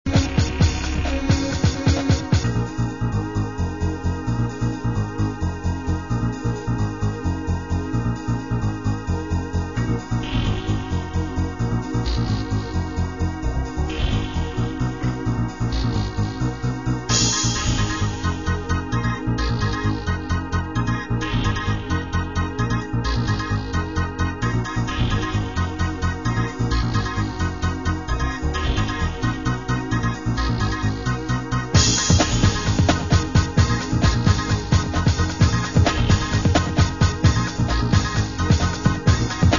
Segunda maqueta con tonos electro-rock bailables.